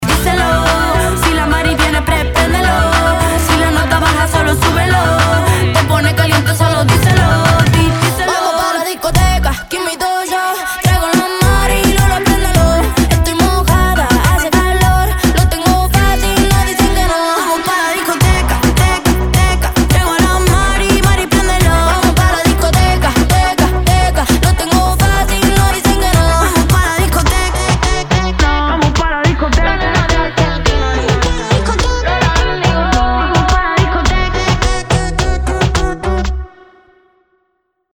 • Качество: 320, Stereo
испанские